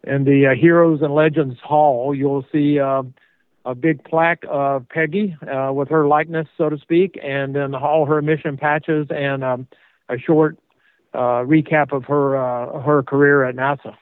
Whitson’s first mission was aboard the Space Shuttle Endeavour in 2002, heard here on NASA-TV: